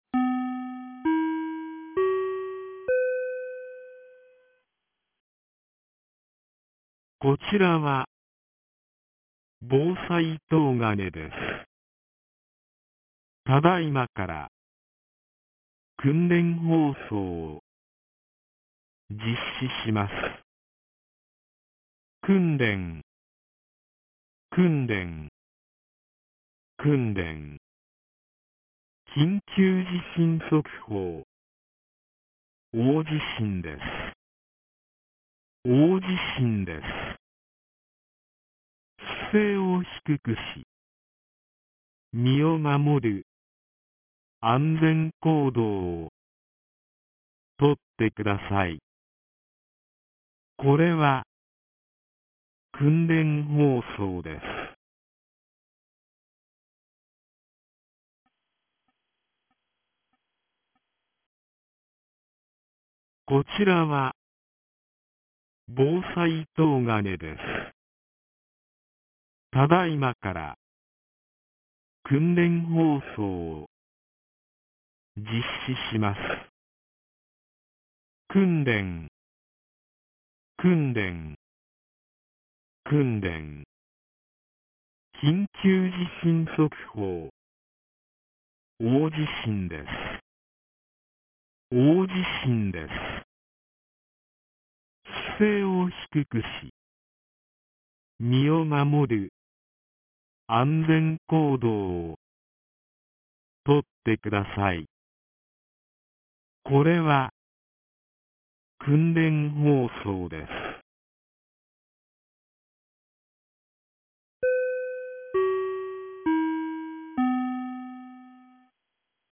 東金市放送内容 2025年11月01日09時02分 【訓練放送】地震発生 | 千葉県東金市メール配信サービス
2025年11月01日 09時02分に、東金市より防災行政無線の放送を行いました。